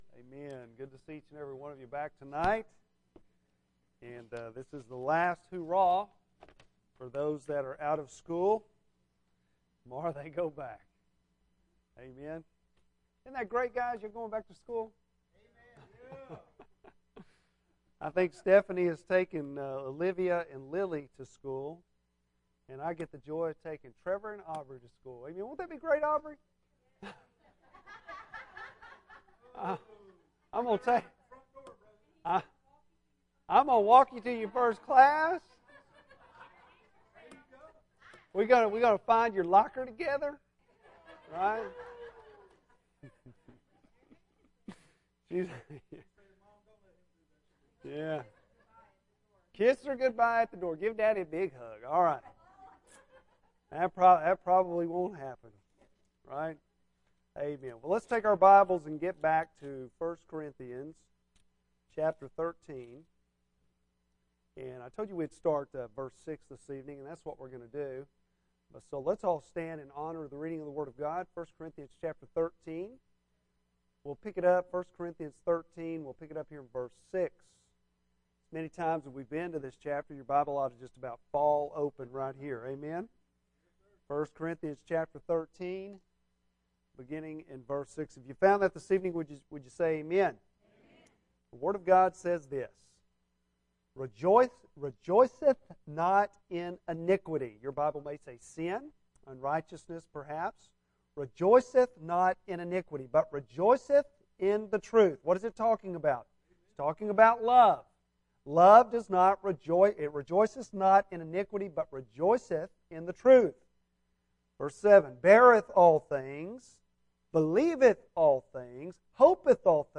Bible Text: I Corinthians 13:6-8 | Preacher